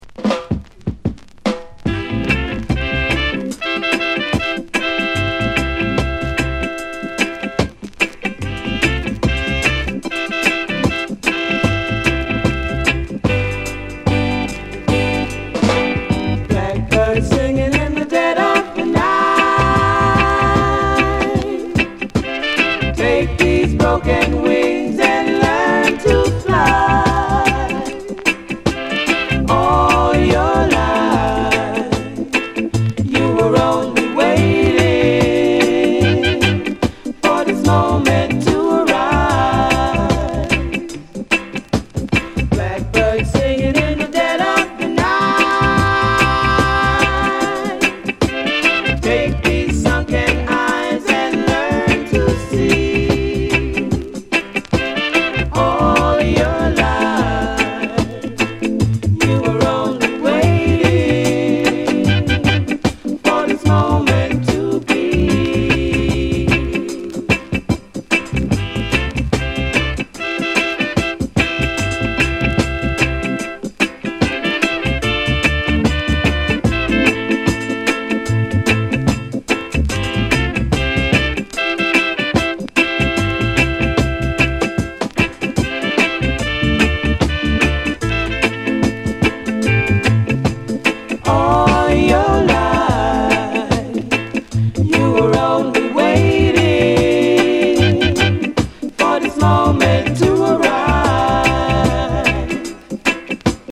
LP]ロックステディーレゲエ